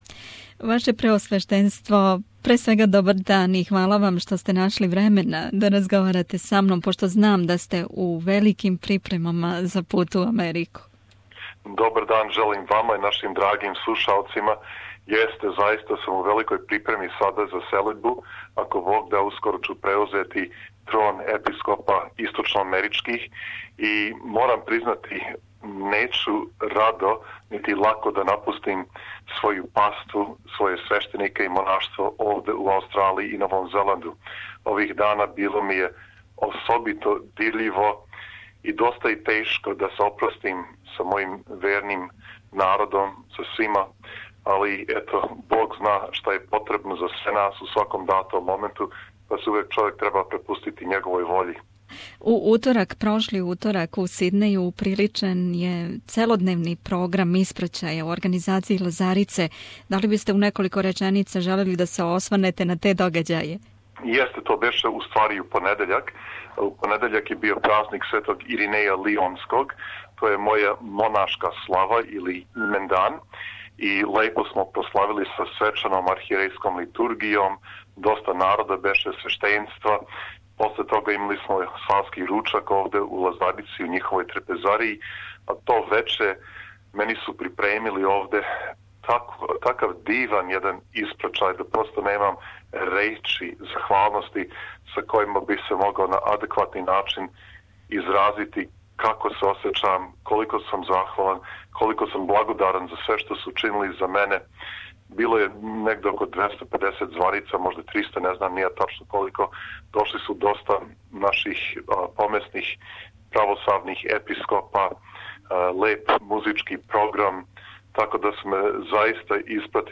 Интервју: Његово Преосвештенство Владика Иринеј
Слушајте ексклузиван разговор са Његовим Преосвештенством Епископом Митрополије аустралијско-новозеландске и новоизабраним источноамеричким Епископом, Г. Иринејом, поводом његовог одласка на нову дужност, после 10-годишње службе у Аустрлији и Новом Зеланду. Разговарали смо о његовој 10-годишњој служби у Аустралиији, о ономе што је постигнуто за то време, о новим изазовима који пред њим стоје у Америци, посебно кад је реч о Српском православном Саборном храму Светог Саве у Њујорку, који је изгорео у пожару.
Bishop Irinej giving an interview to SBS in Canberra in 2014.